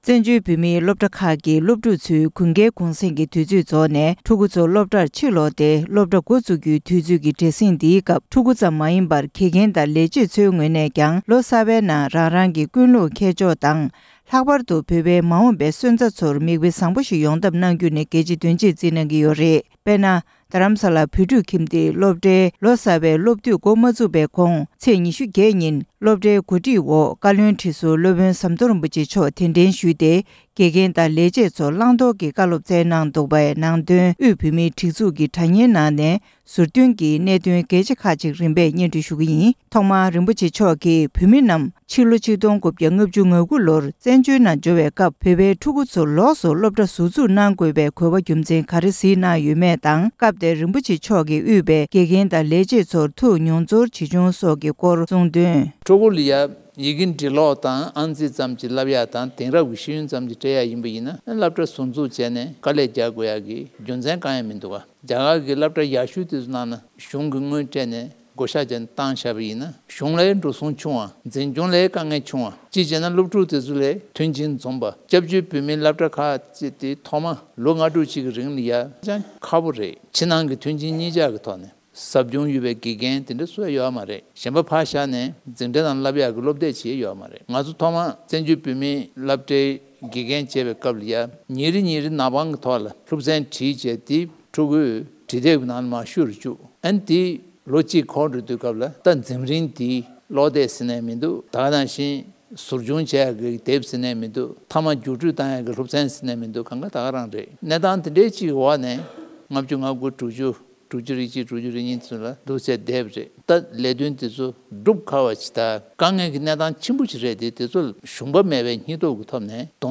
མཁས་དབང་ཟམ་གདོང་རིན་པོ་ཆེ་མཆོག་གིས་རྡ་སྟེང་བོད་ཁྱིམ་གྱི་དགེ་ལས་ཡོངས་ལ་བཟང་སྤྱོད་སྐོར་བཀའ་སློབ་གནང་འདུག